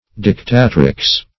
dictatrix - definition of dictatrix - synonyms, pronunciation, spelling from Free Dictionary Search Result for " dictatrix" : The Collaborative International Dictionary of English v.0.48: Dictatrix \Dic*ta"trix\, n. [L.]